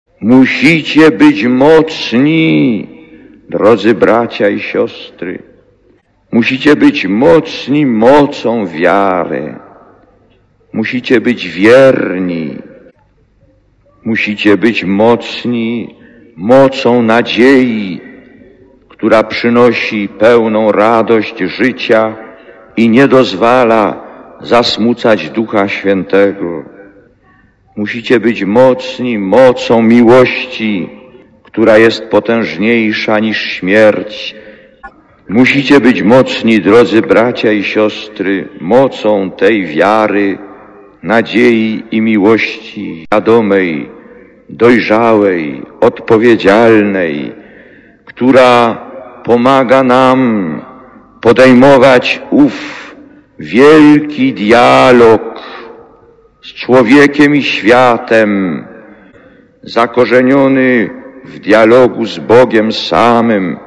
Lektor: Z homilii w czasie Mszy św. (por. tamże -